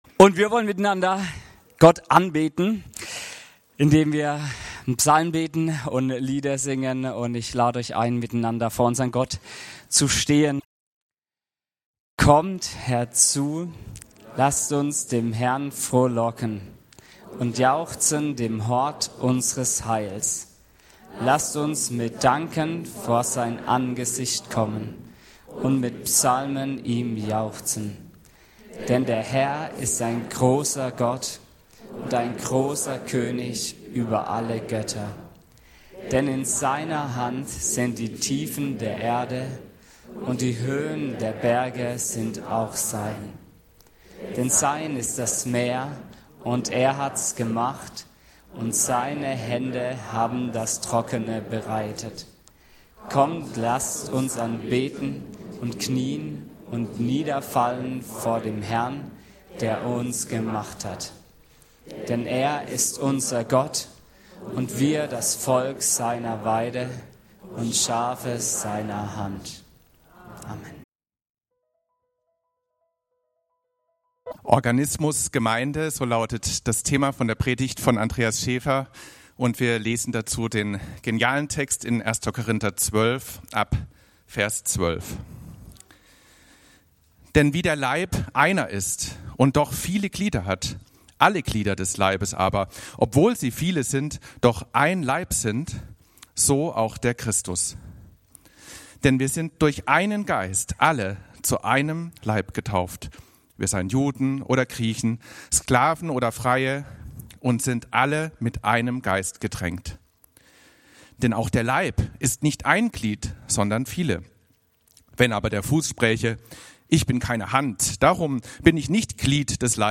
Organismus Gemeinde - Gottesdienst